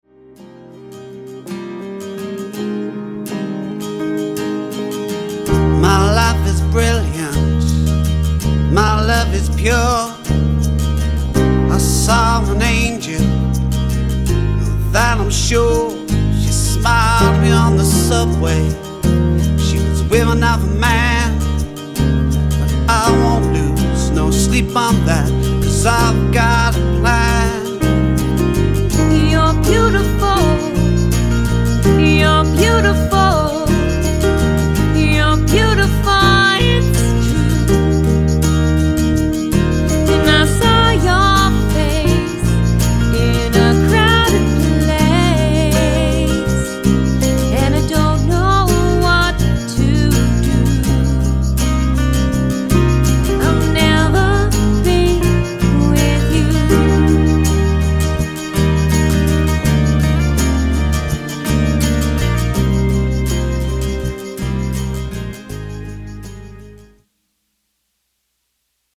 Guitar
Keyboards